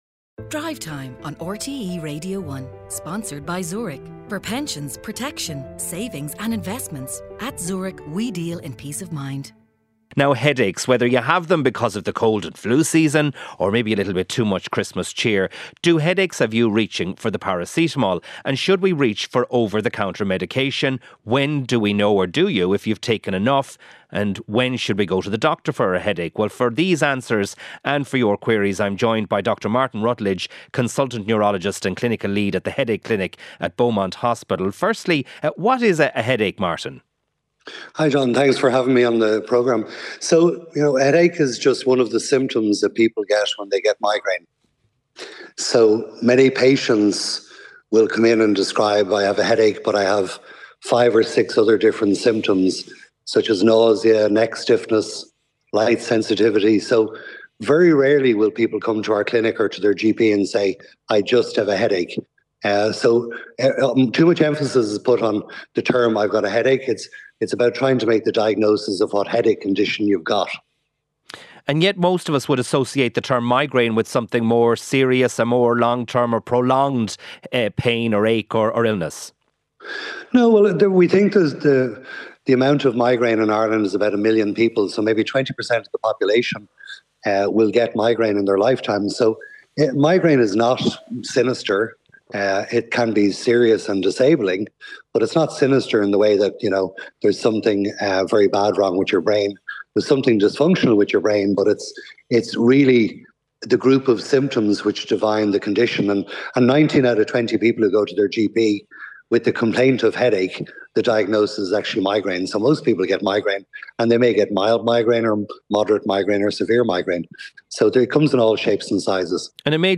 Highlights from the daily news programme with Sarah McInerney and Cormac Ó hEadhra. Featuring all the latest stories, interviews and special reports.